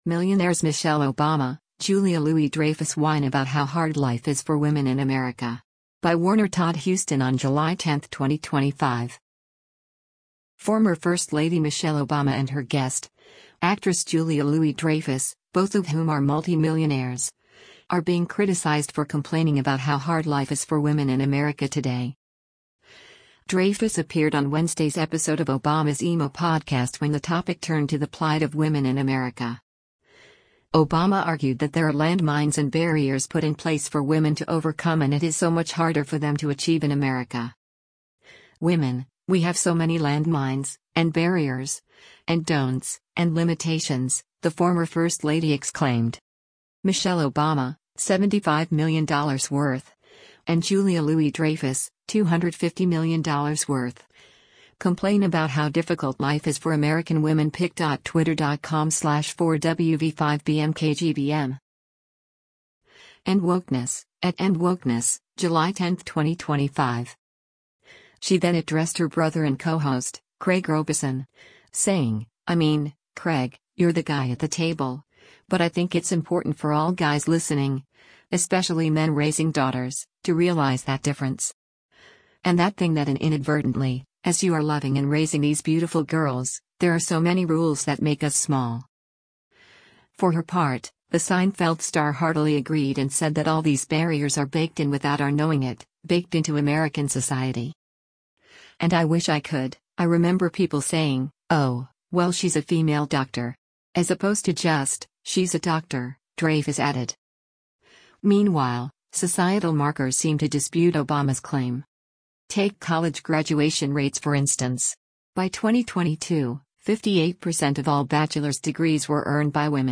Dreyfus appeared on Wednesday’s episode of Obama’s IMO podcast when the topic turned to the plight of women in America.